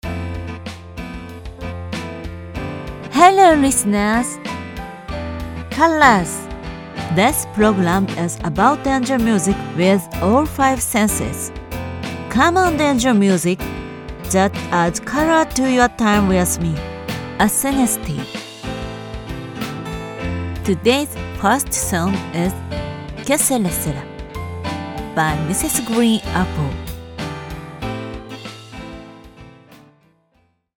Ideal for corporate narration, IVR, animation, and commercials, she delivers professional voice over services with broadcast-level clarity and speed.
Commercial Demo